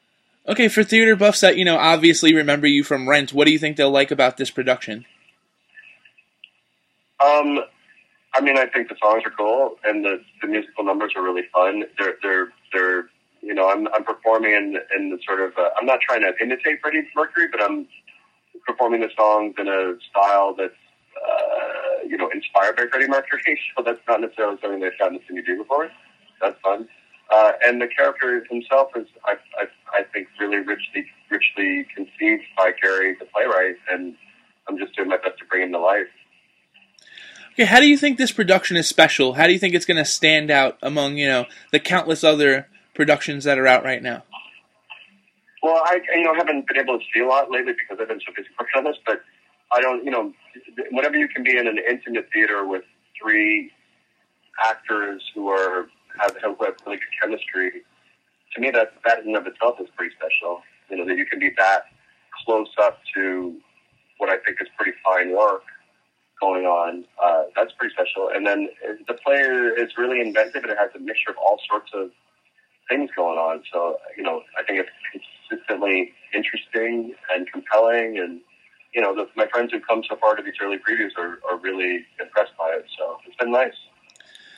Review Fix: Audio Interview with Broadway Legend Anthony Rapp- Sneak Peek